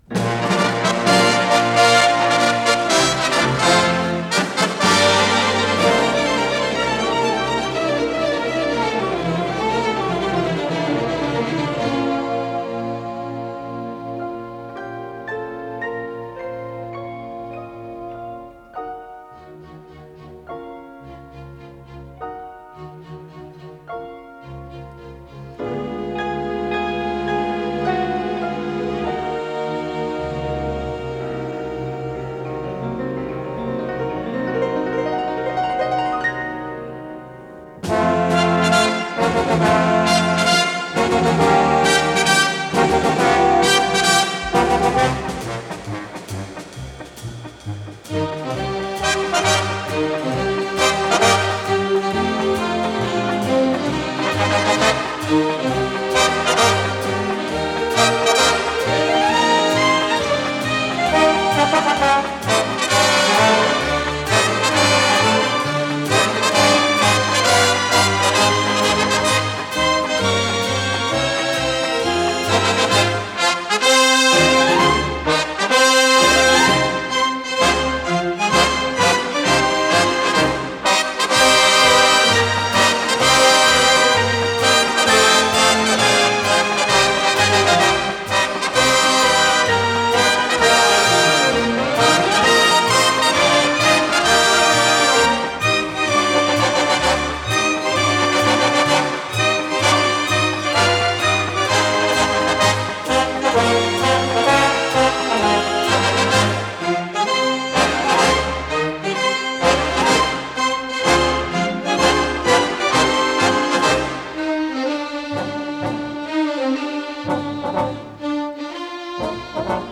ПодзаголовокФа мажор
ВариантДубль моно